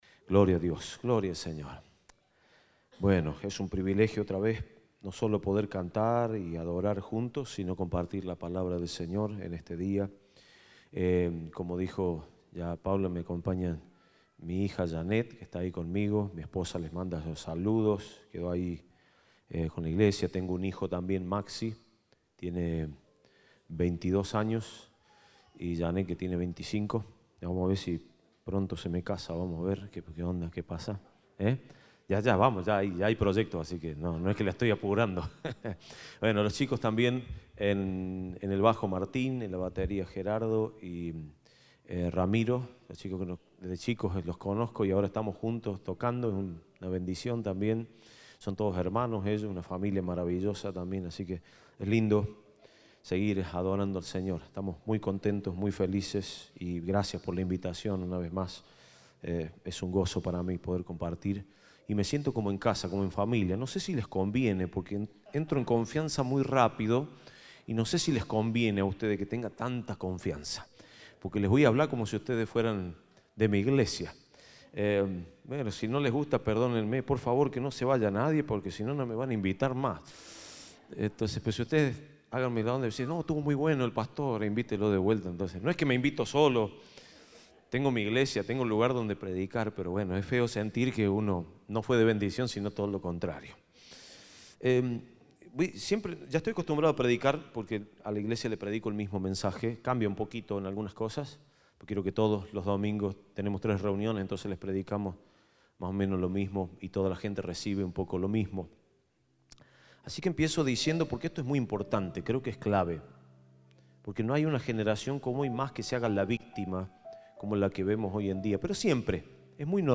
Compartimos el mensaje del Domingo 15 de Agosto de 2021